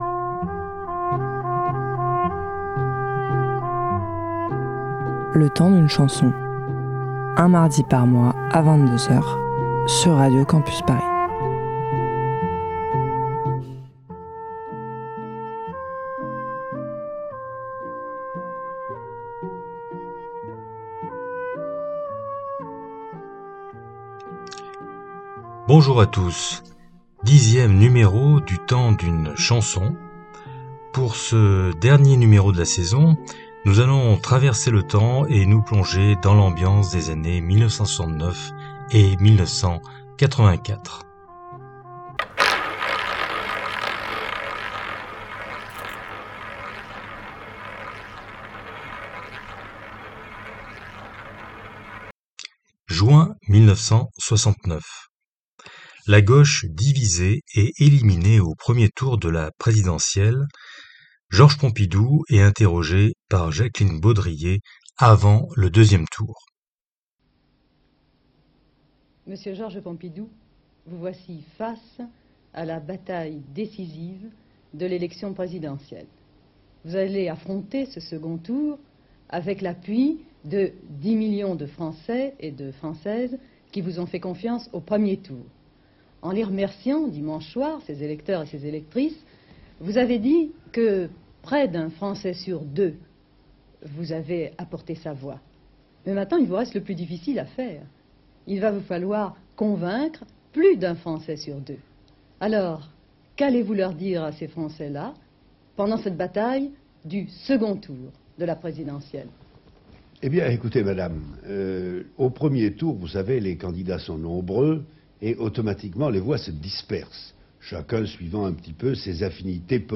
Musicale Pop & Rock